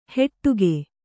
♪ heṭṭuge